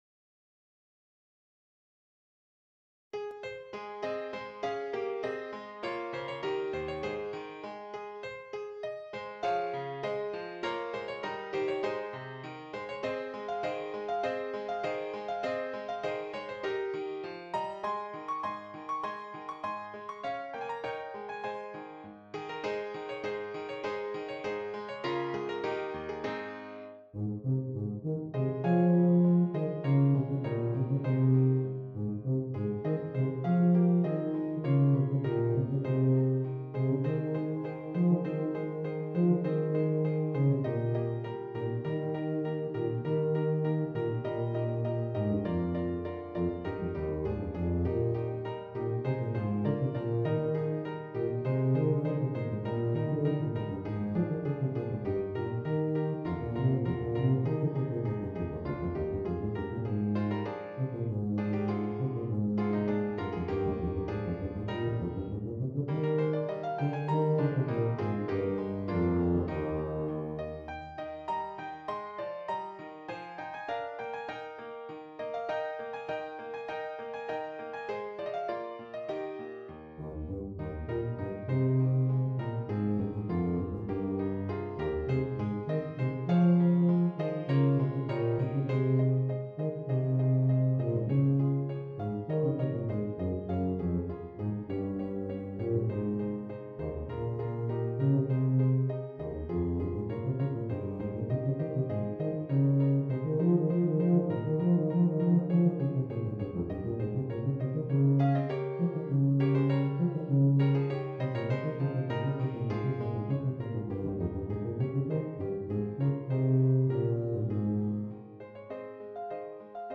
Tuba and Keyboard